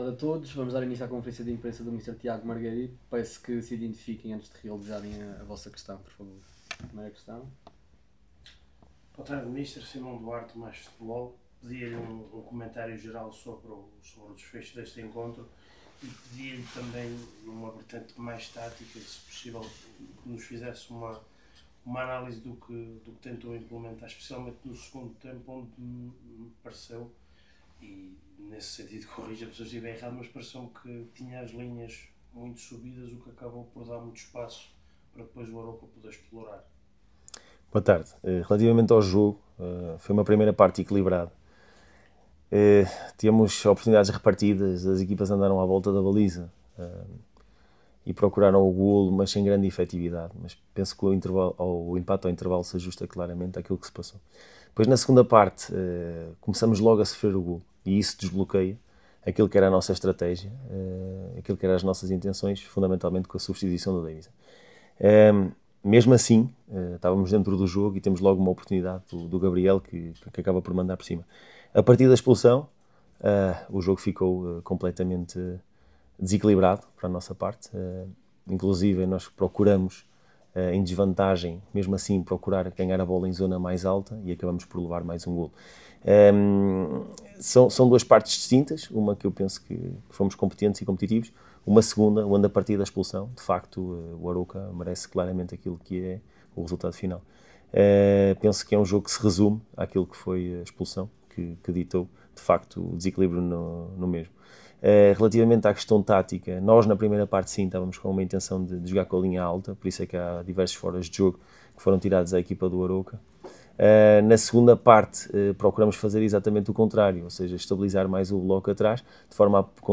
Na conferência de imprensa realizada no final do encontro para a 23.ª jornada da Liga Portugal Betclic